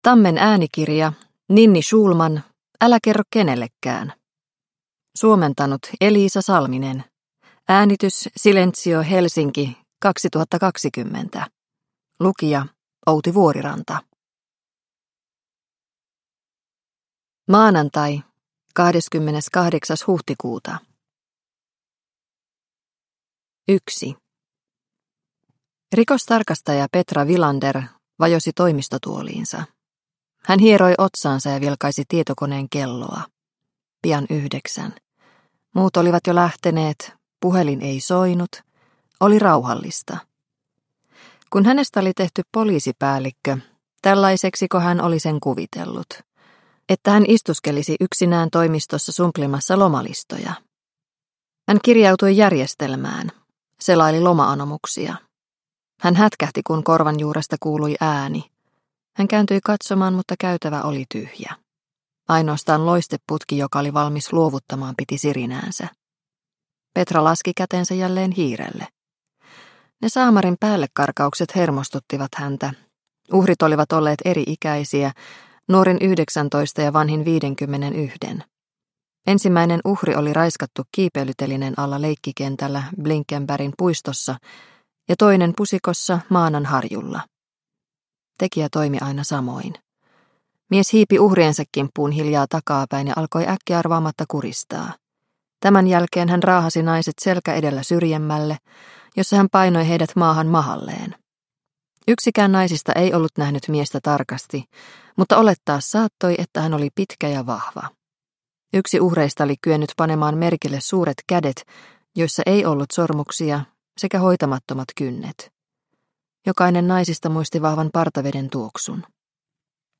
Älä kerro kenellekään – Ljudbok – Laddas ner